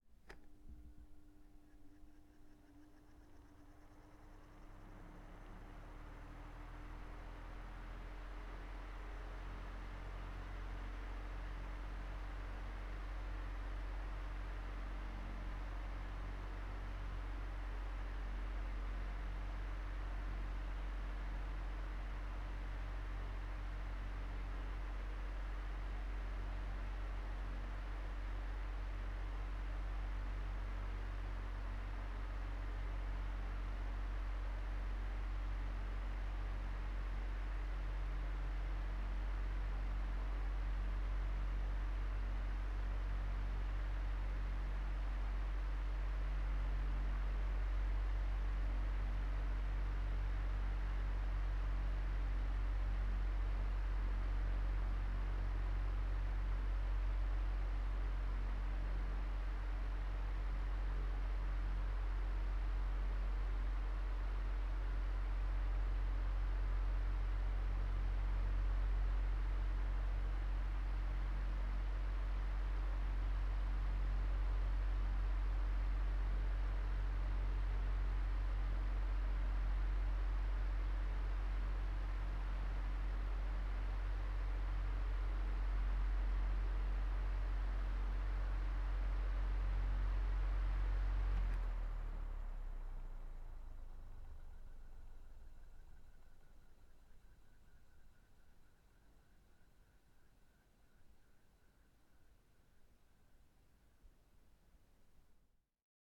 fan.ogg